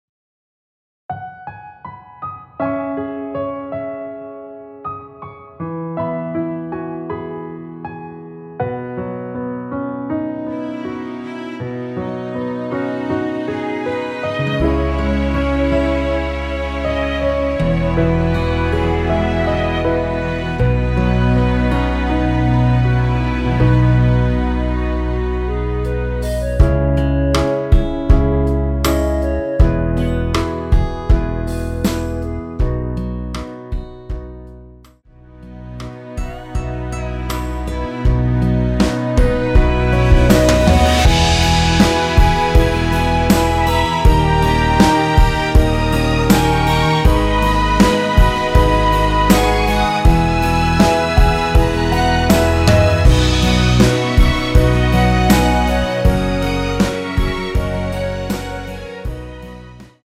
원키에서(+5)올린 멜로디 포함된 MR입니다.
앞부분30초, 뒷부분30초씩 편집해서 올려 드리고 있습니다.